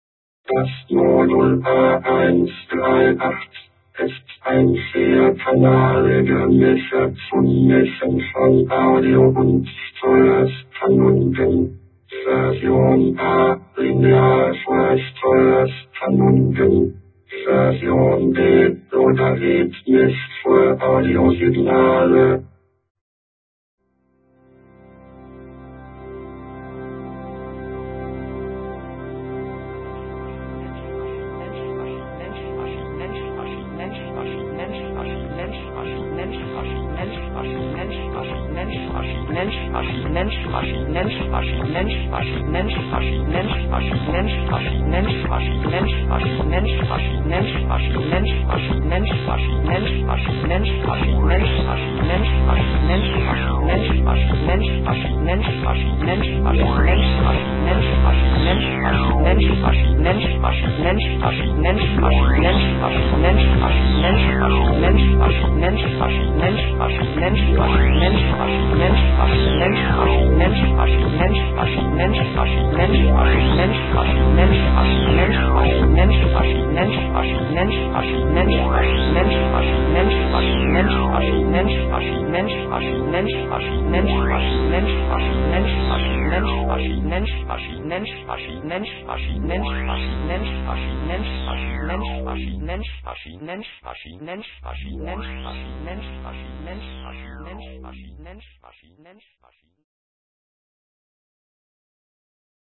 Allgemein:  Wir haben die MP3-Files bewußt mit einer ziemlich schlechten Qualität, also einer Datenrate von nur 16 kbps codiert (CD-Qualität wäre ca. 128-160 kbps).
All tracks of our A-100 demo CD (no longer available)